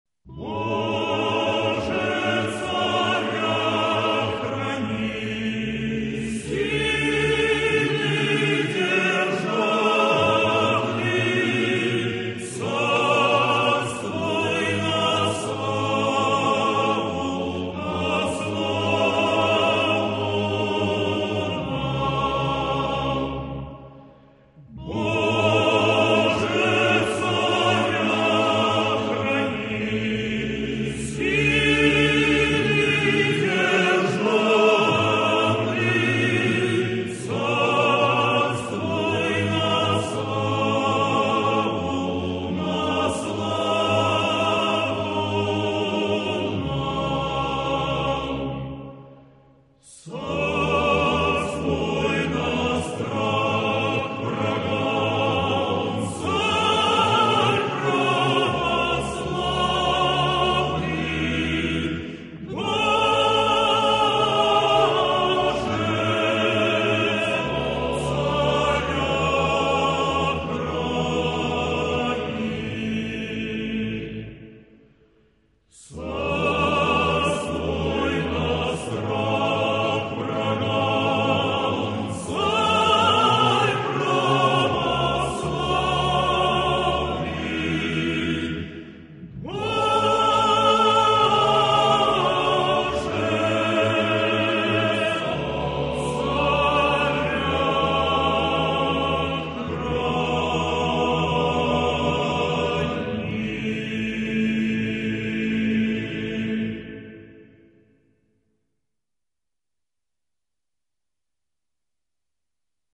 Гимн Российской Империи 1833-1917 гг.
музыка Алексея Федоровича Львова (1833)
слова Василия Андреевича Жуковского (1833)
Исполняет: Мужской хор Валаамского монастыря